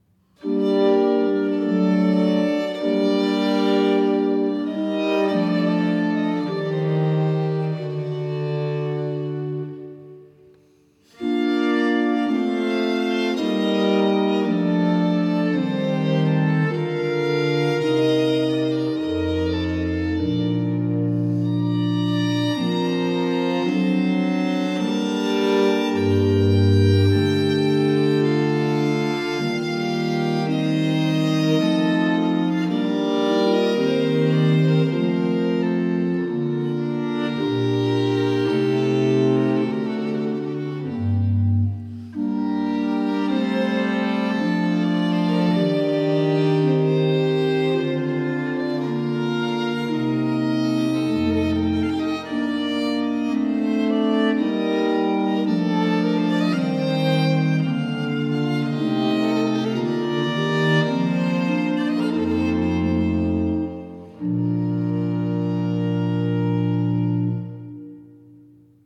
Grave